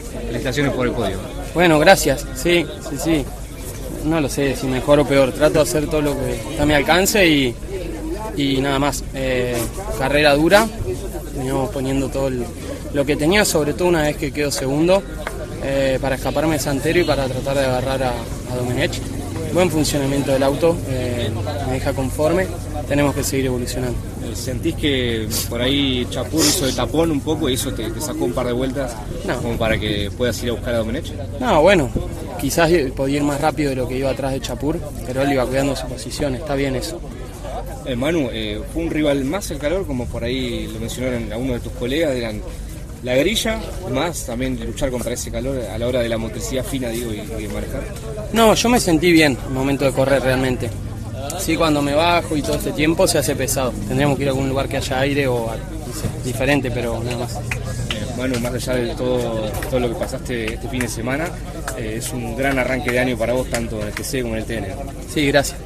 Todas las entrevistas, a continuación y en el orden antes mencionado: